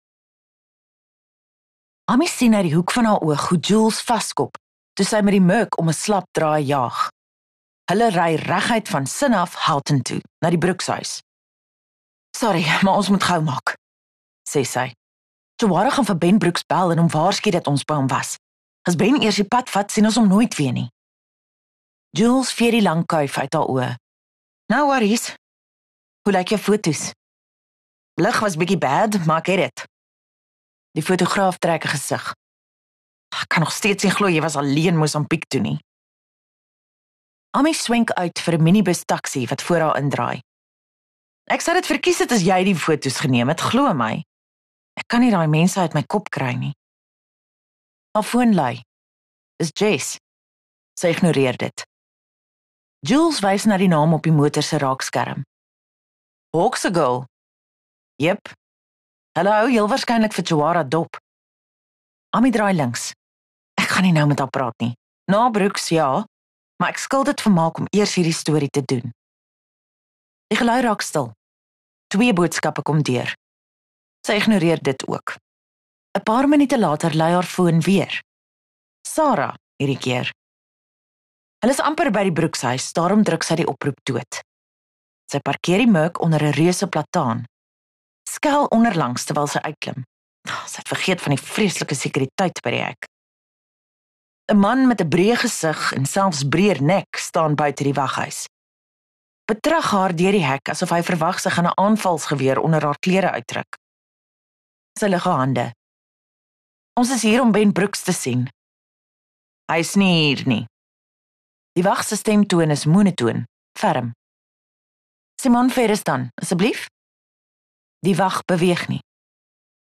View Promo Continue Install Netwerk24 Luisterboek: Al wat tel #60 59_Al wat tel_Ami sien uit die hoek van haar oog 8 MIN Download (3.8 MB) AF SOUTH AFRICA 00:00 Playback speed Skip backwards 15 seconds